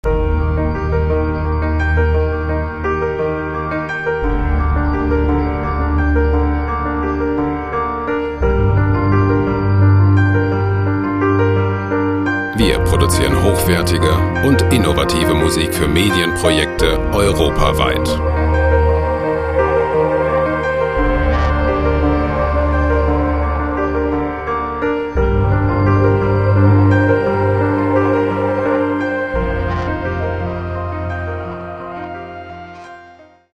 gemafreie Meditationsmusik - Loops
Musikstil: Romantic Piano
Tempo: 86 bpm